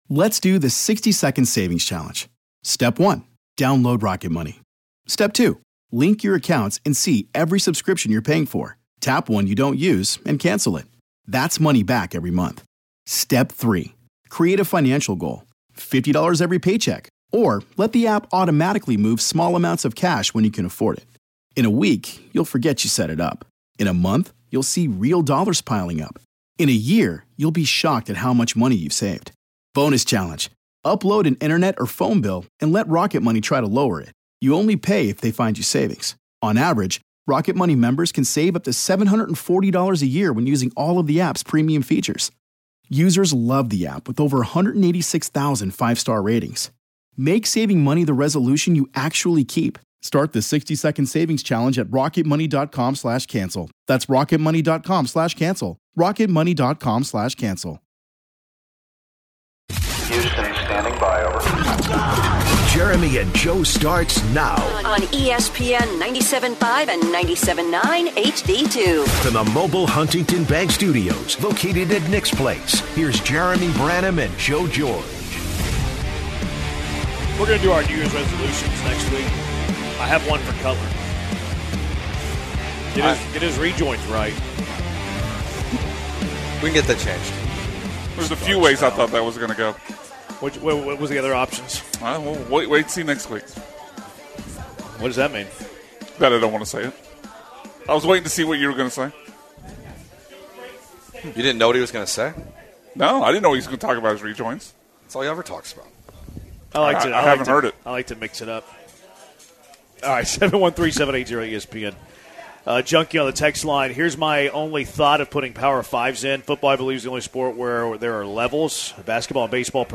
12/22 Hour 3- Reacting To The First Round Of The CFP + Time To Panic About The Rockets?! - At Nick's Place For The 2025 Christmas Party!